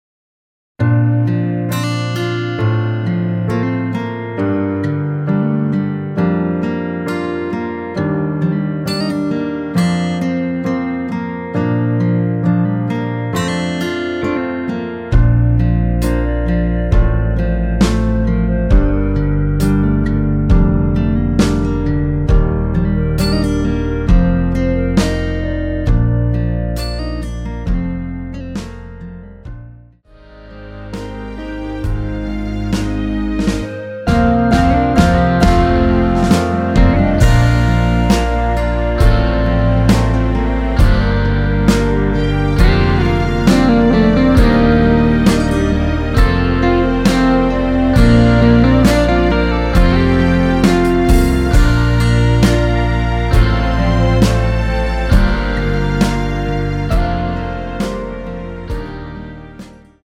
원키에서(+2) 올린 멜로디 포함된 MR 입니다.(미리듣기 참조)
Bb
앞부분30초, 뒷부분30초씩 편집해서 올려 드리고 있습니다.
중간에 음이 끈어지고 다시 나오는 이유는